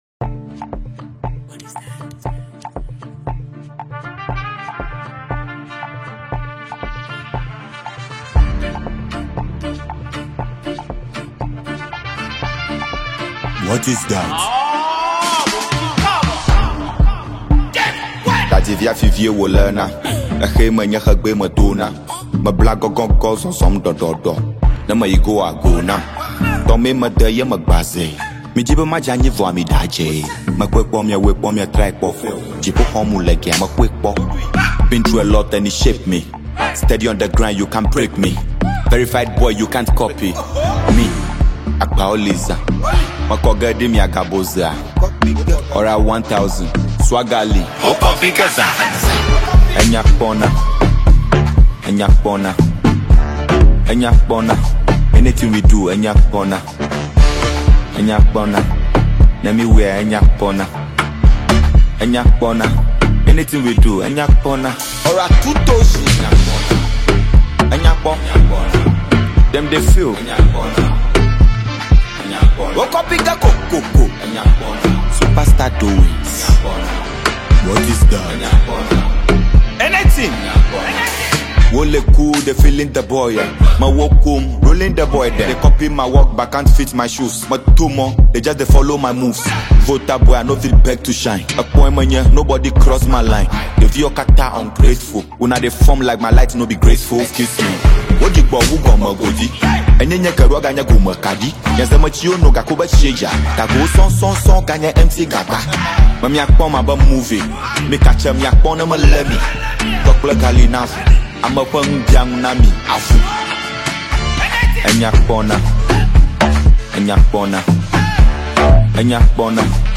Ghana Music
High-Energy Ewe-Infused Anthem